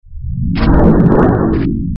Download Portal sound effect for free.
Portal